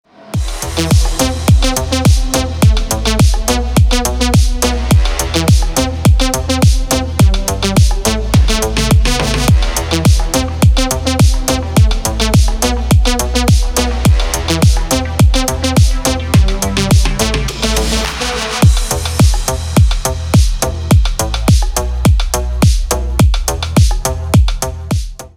Клубная нарезка на рингтон